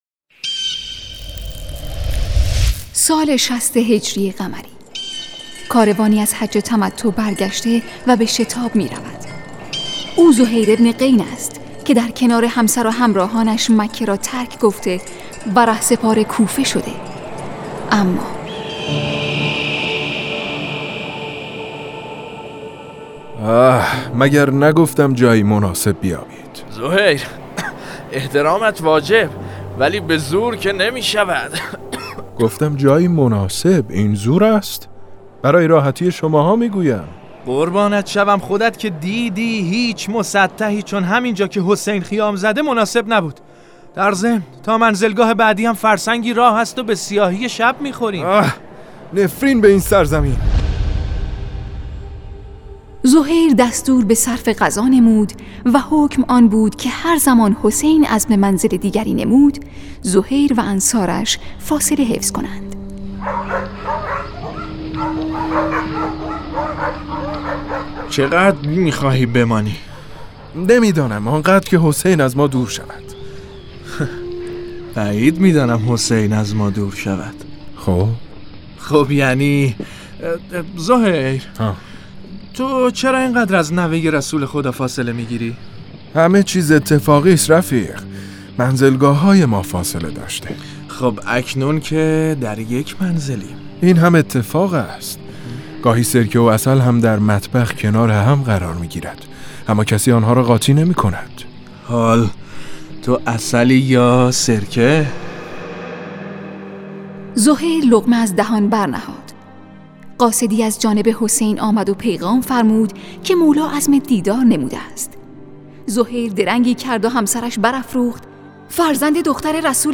نمایش صوتی رندان تشنه لب